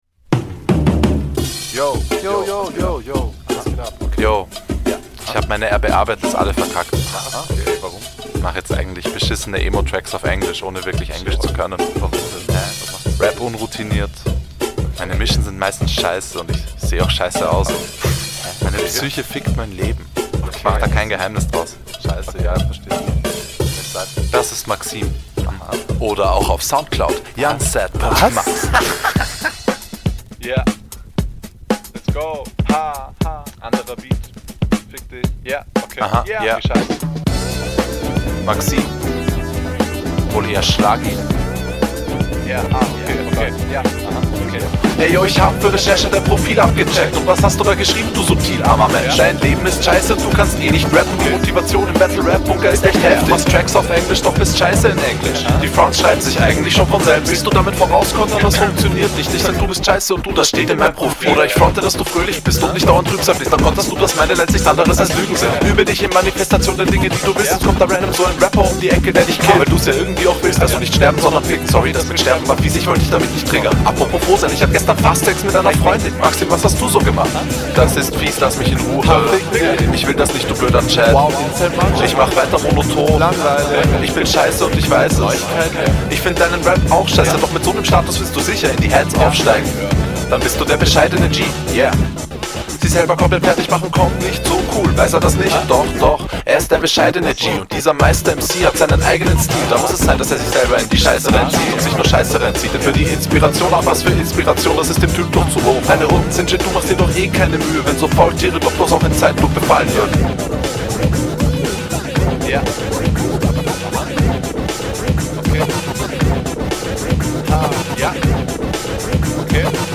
Flow: Der flow geht richtig ab auf den Beat, aber gleichzeitig hast du immernoch diesen …
Der Stimmeinsatz ist wie gewohnt cool, die Delivery ist sehr eigen, aber stylisch, das alles …